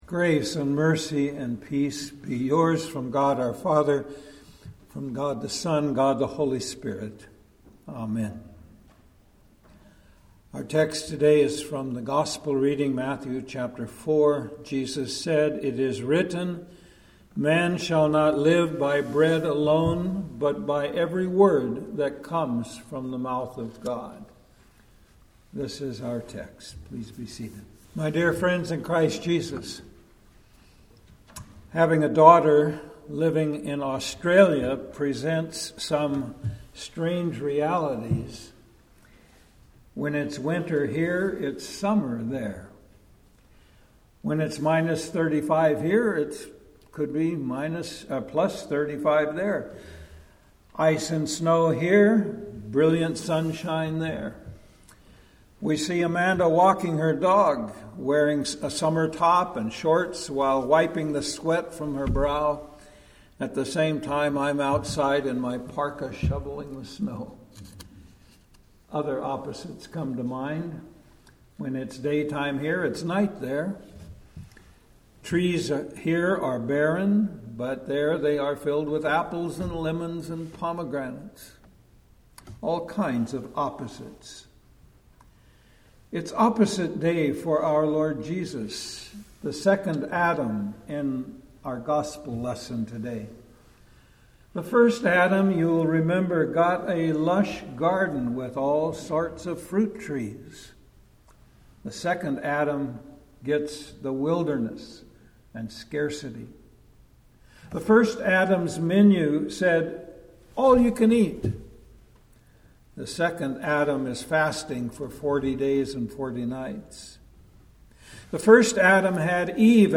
Sermons – Redeemer Lutheran Church